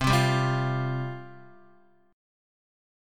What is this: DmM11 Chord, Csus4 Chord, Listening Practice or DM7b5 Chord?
Csus4 Chord